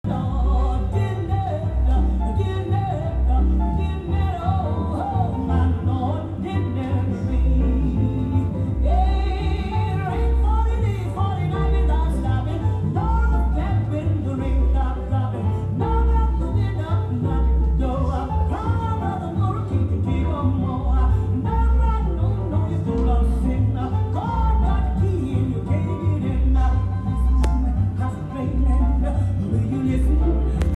Gênero: Gospel, Jazz
com a reprodução original do disco anunciado: